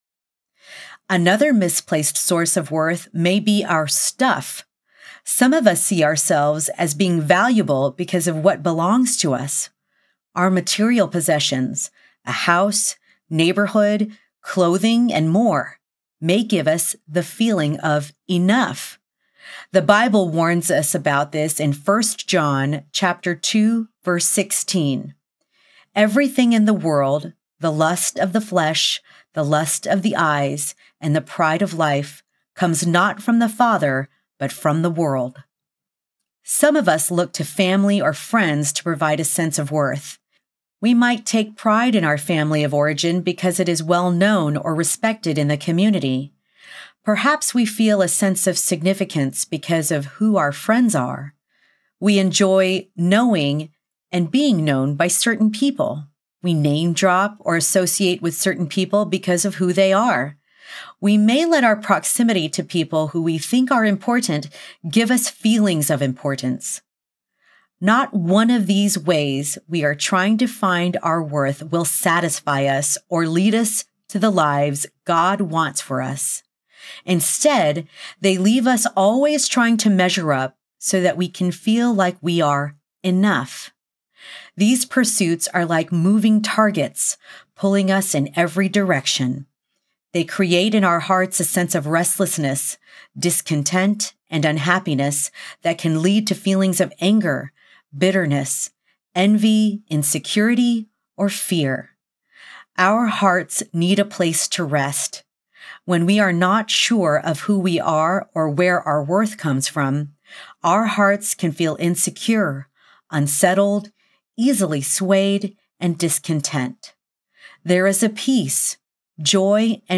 The Better Mom Audiobook
Narrator
5.75 Hrs. – Unabridged